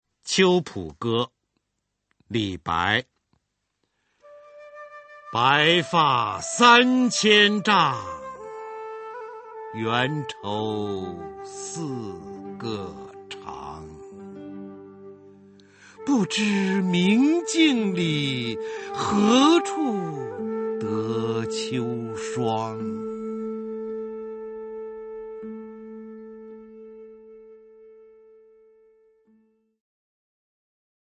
[隋唐诗词诵读]李白-秋浦歌（男） 唐诗吟诵